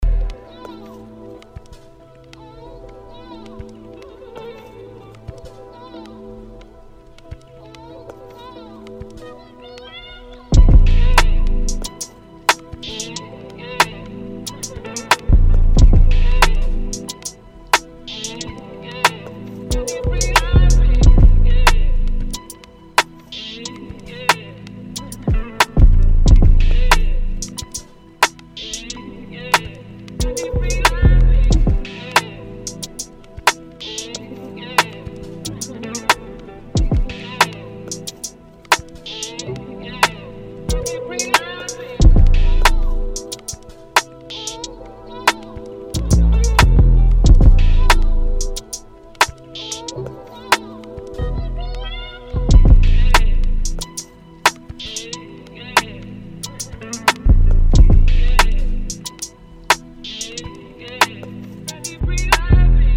Hip Hop, Trap
A Minor